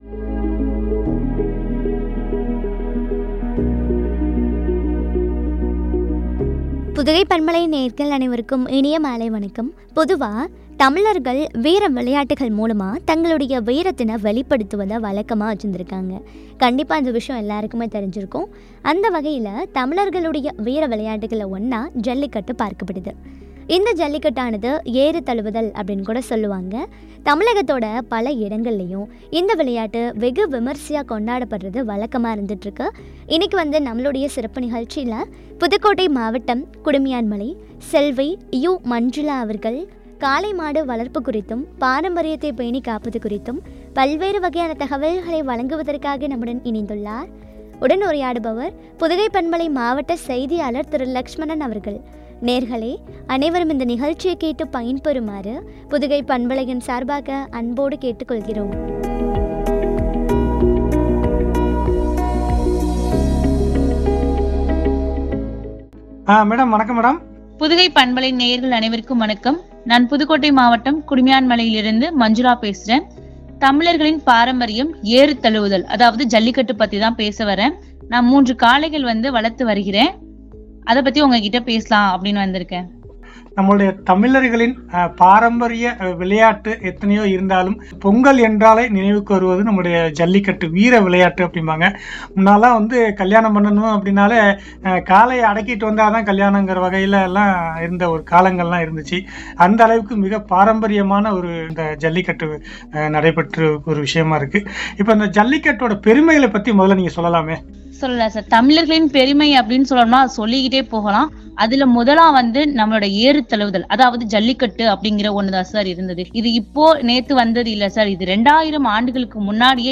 வீரத்தைப் பறைசாற்றுவோம்” குறித்து வழங்கிய உரையாடல்.